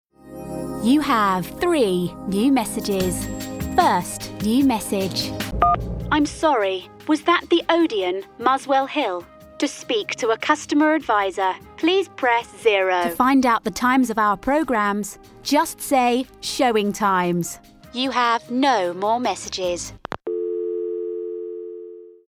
Engels (Brits)
Natuurlijk, Veelzijdig, Vriendelijk, Warm, Zakelijk
Telefonie